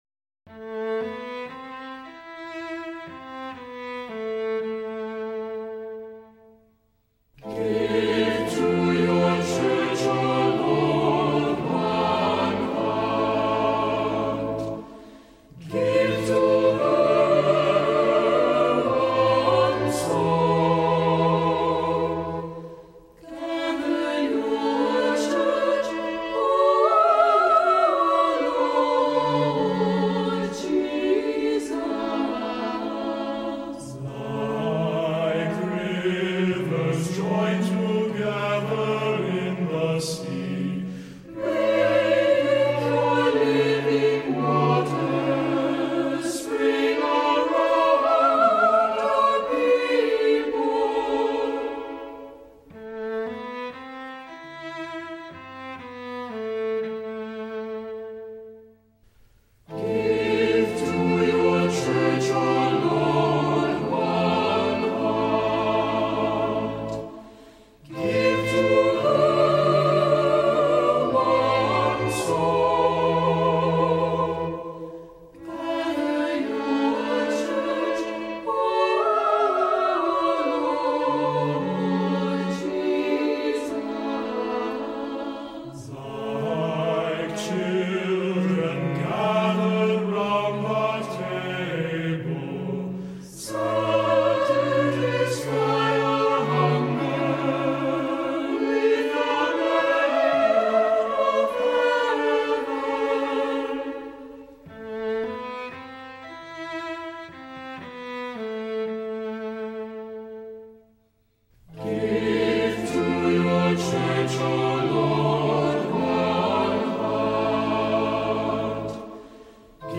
Voicing: SATB,Cantor,Assembly